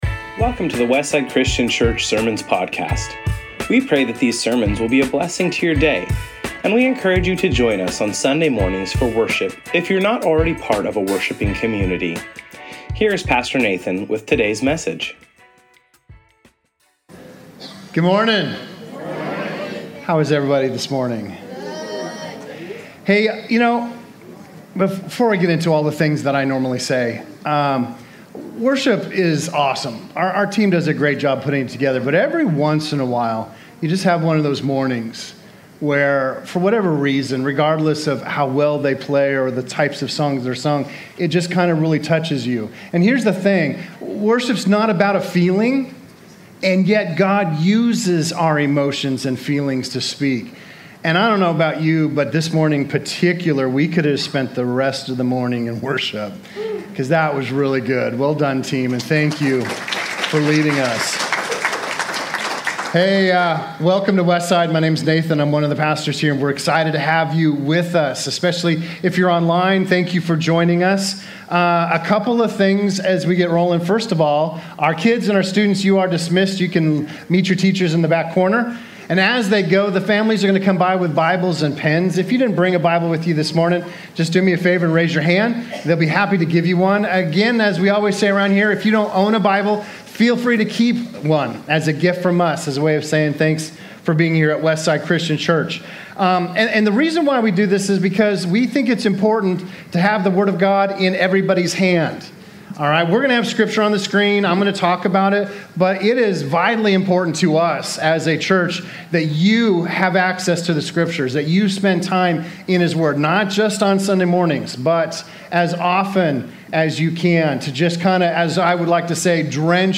Category: Sermons